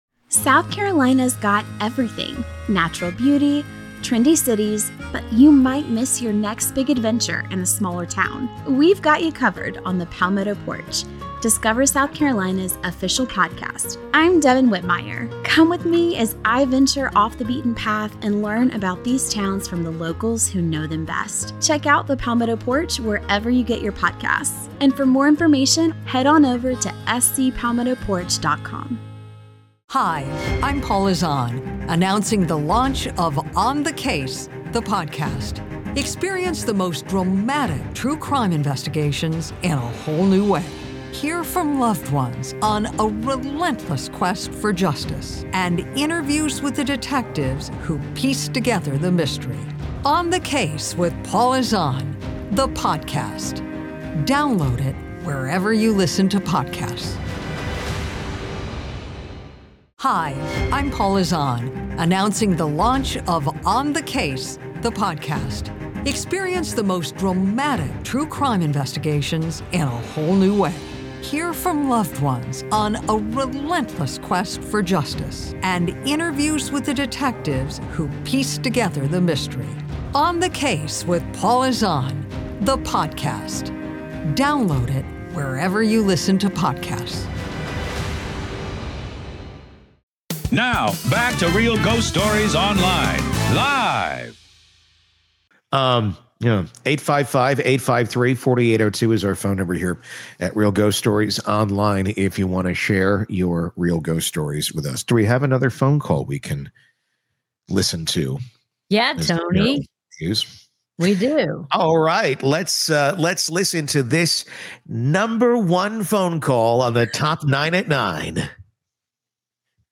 A caller shares his chilling encounter with a shadow cat that vanishes into walls—and his cousin sees the same thing.